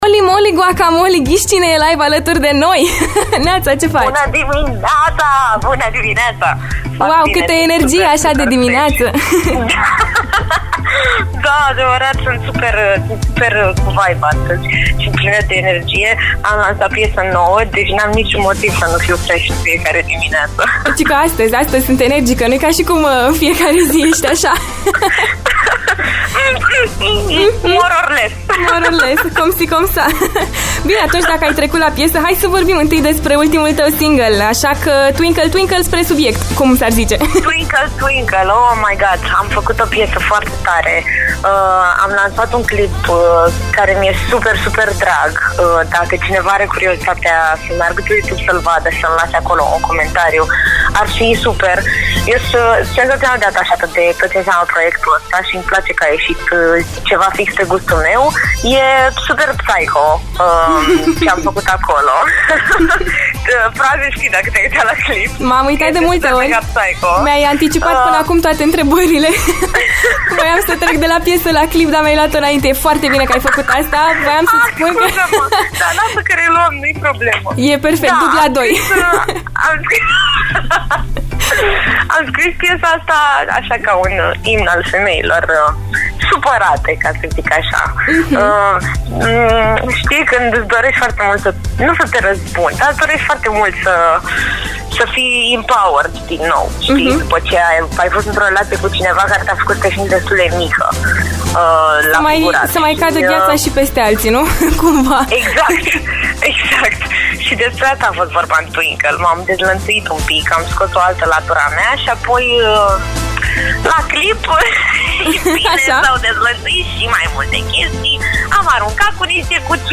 Holly Moly este o artistă extrem de senzuală, cu o voce a cărei profunzime rămâne întipărită în minte.